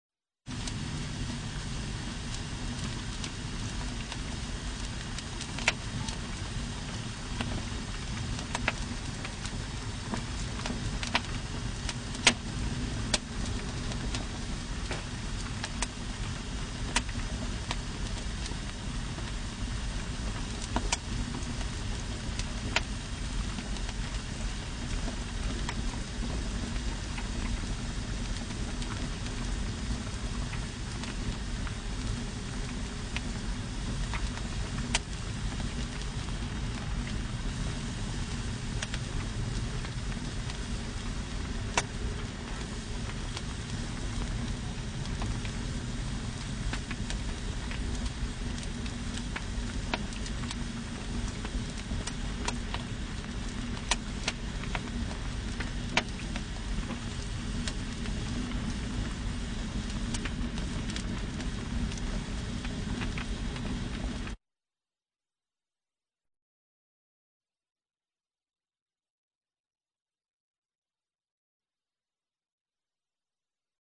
Звук костра, гудение пламени в печи, звук потрескивания языков пламени в камине можно слушать и скачать здесь.
Звучит довольно большой костер с сильными тресками поленьев — продолжительность: 1мин 13 сек
Zvuk_plameni.mp3